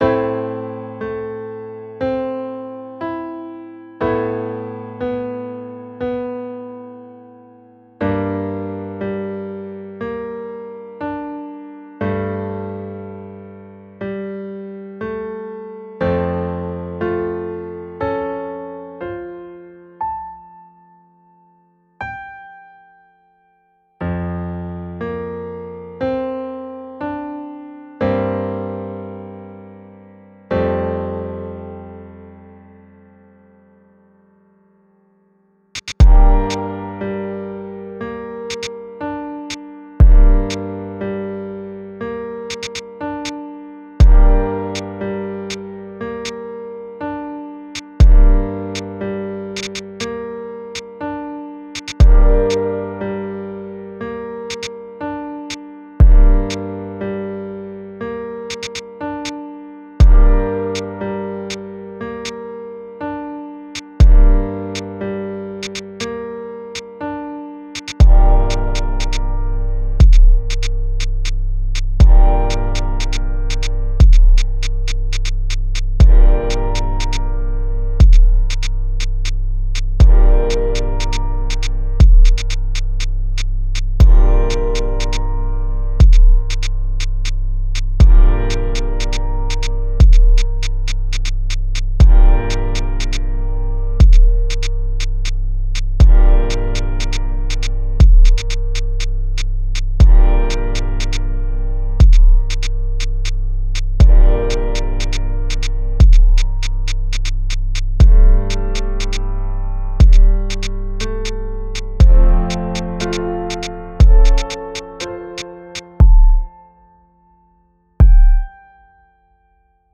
Simple and sweet. Piano intro with a bet over a looping chord